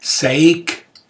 Details zum Wort: se-ig. Mundart Begriff für selbe